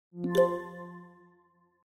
Звуки подключения зарядки
Звук зарядки телефона Xiaomi